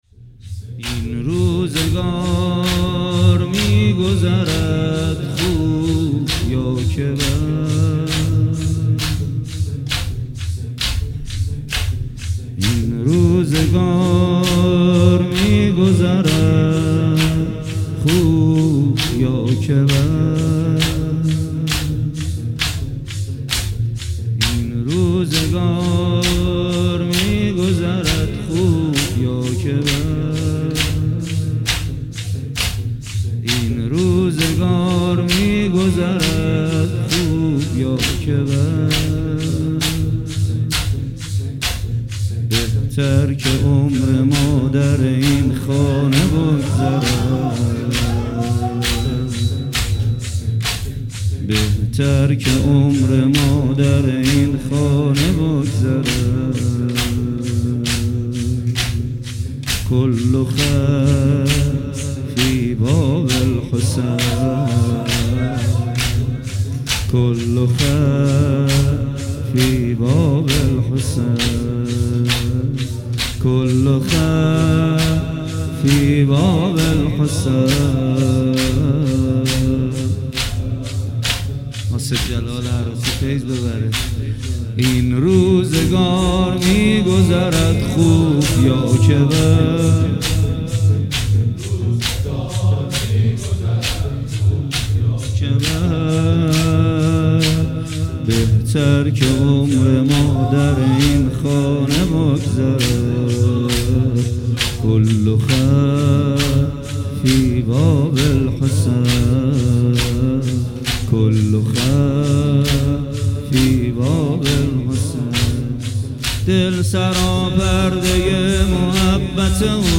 15 بهمن 98 - تک - این روزگار می گذرد